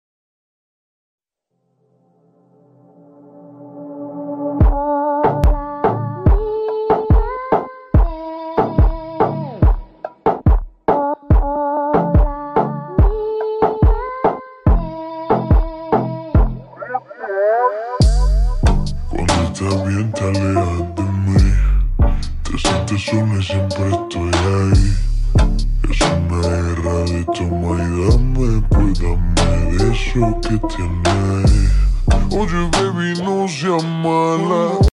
slowed version